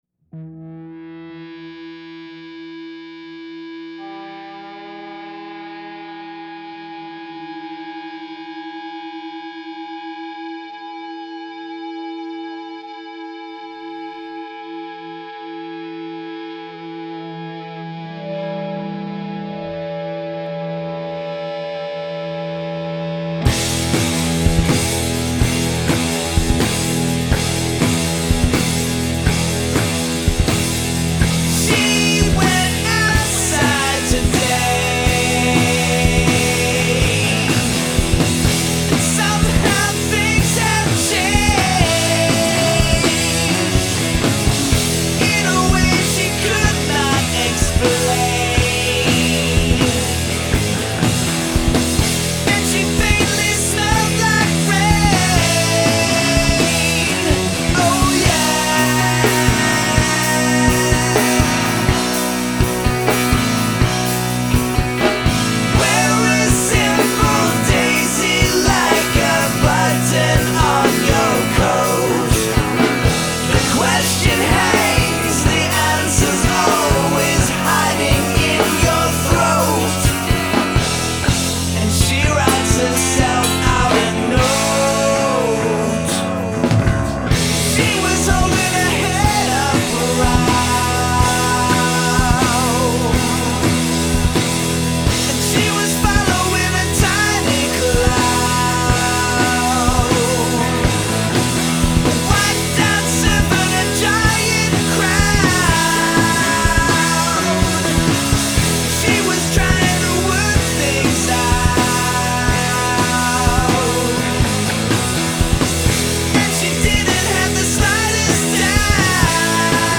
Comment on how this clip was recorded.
Some mixing issues, but I want to get your take on it. Vocals too loud? Guitar too quiet?